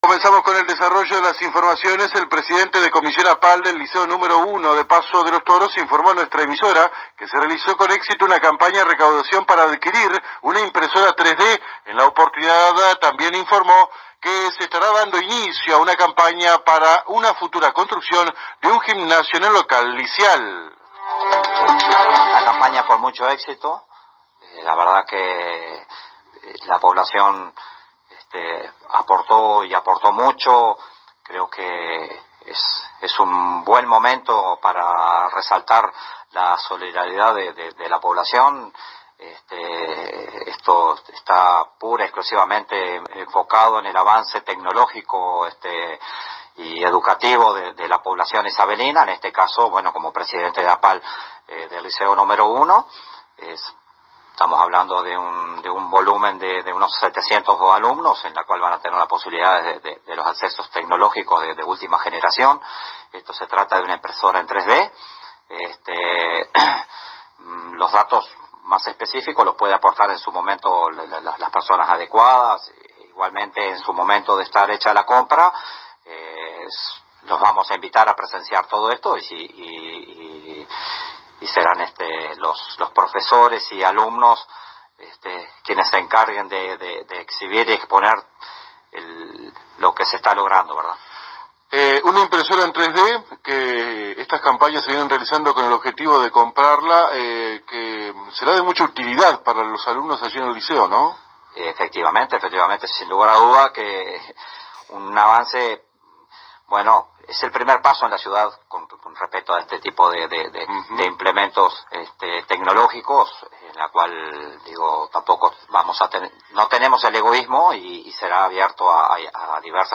en visita a los estudios de la AM 1110 de nuestra ciudad